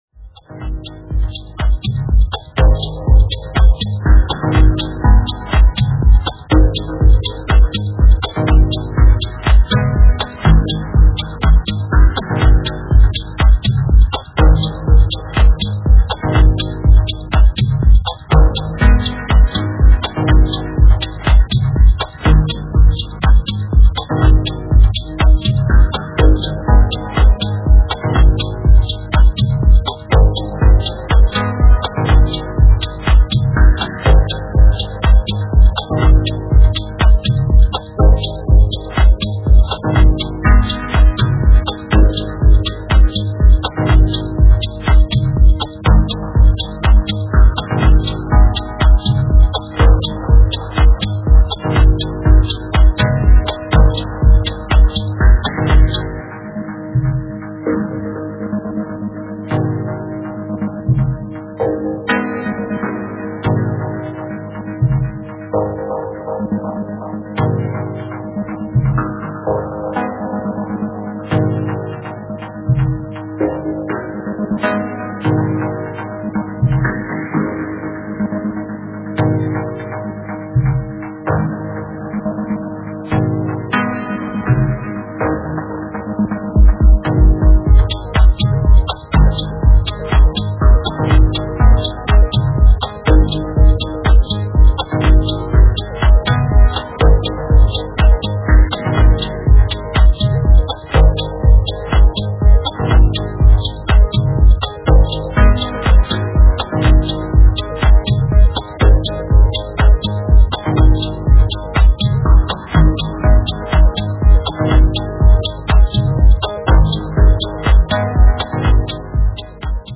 deep & grooving style